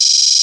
Gamer World Open Hat 5.wav